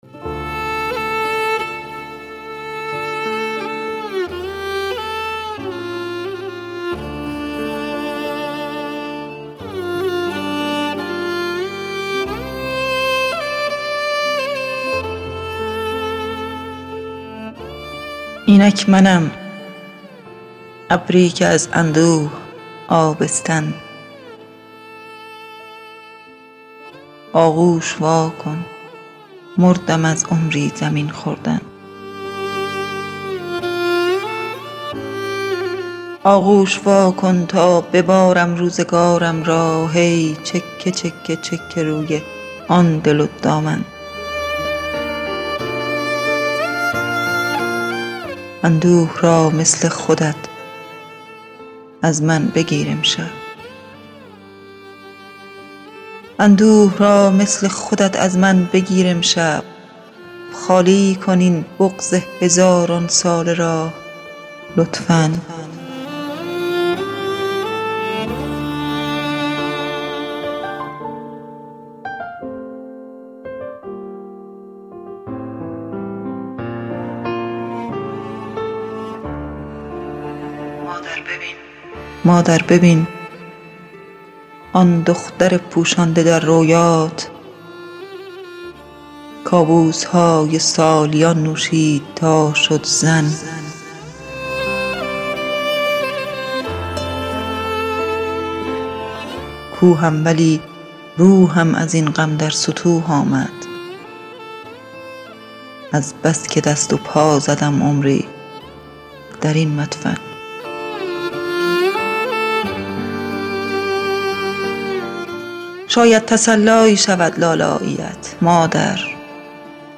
دکلمه
میکس و مسترینگ